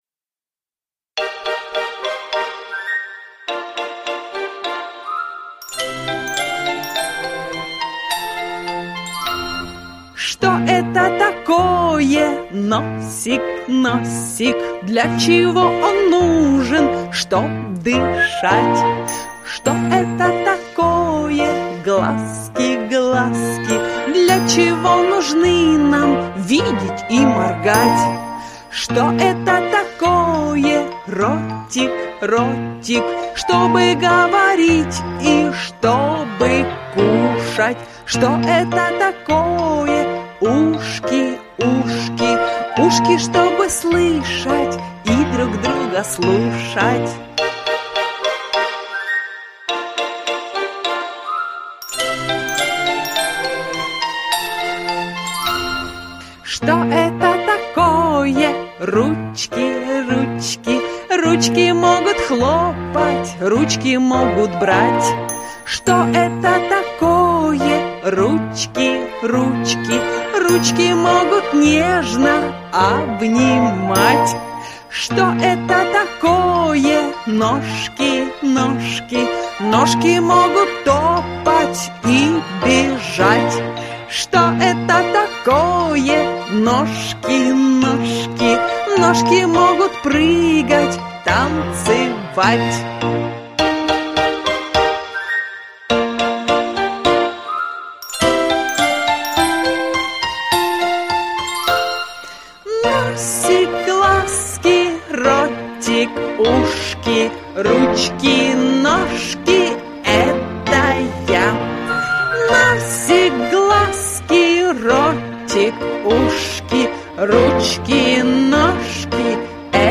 Что это такое? - песенка с движениями - слушать онлайн